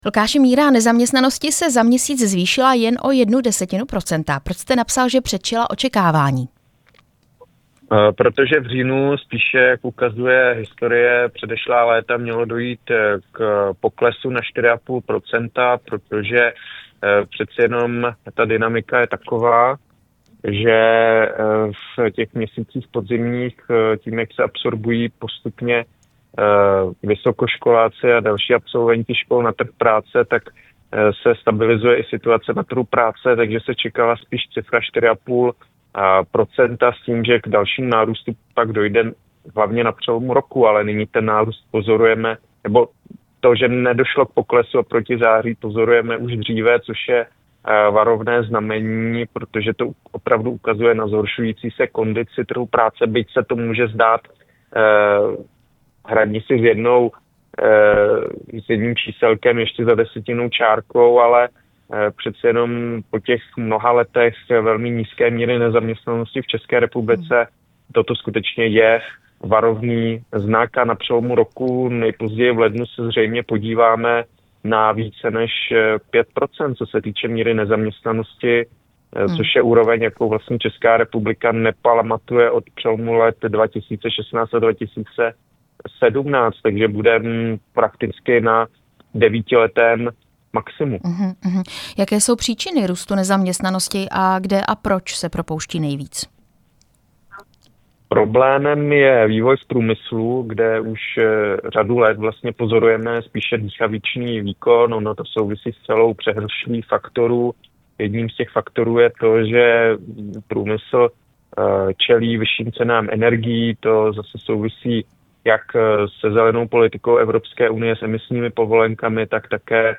Rozhovor s ekonomem Lukášem Kovandou